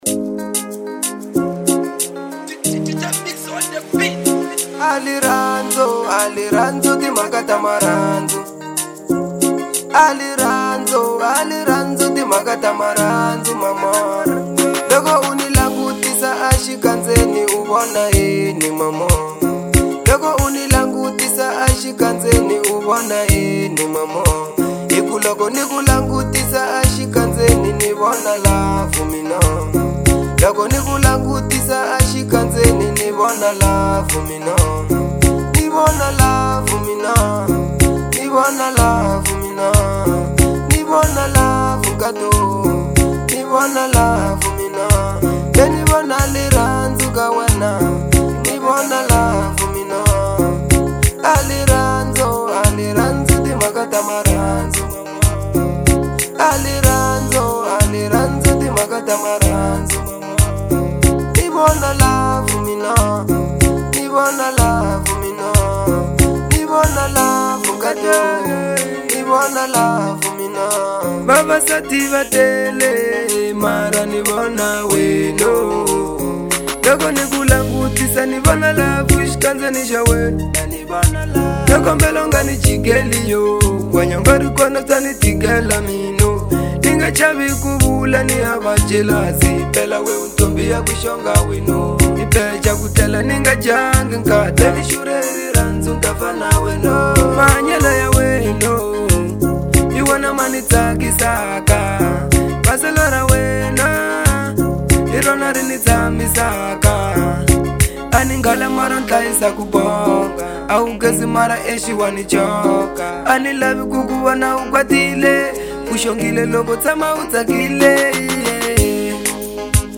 02:06 Genre : Afro Pop Size